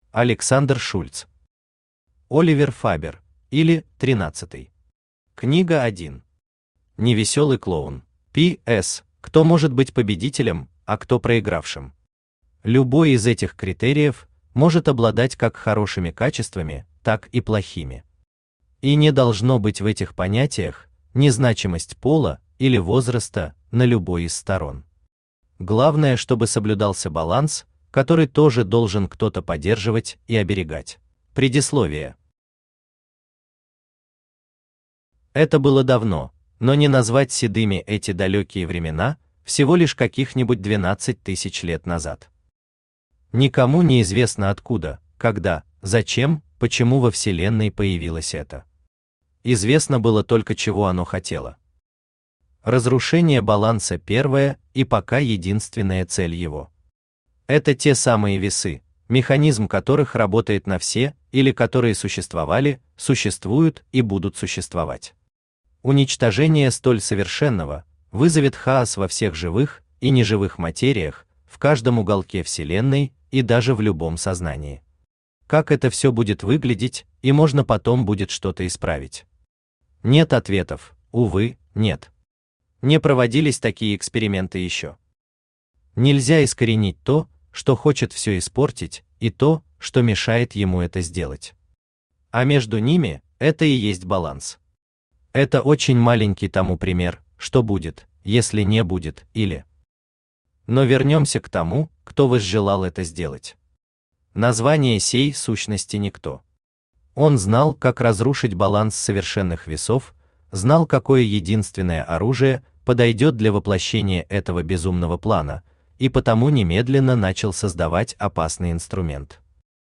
Аудиокнига Оливер Фабер: ИЛИ…13ый. Книга 1. Невеселый Клоун | Библиотека аудиокниг
Невеселый Клоун Автор Александр Шульц Читает аудиокнигу Авточтец ЛитРес.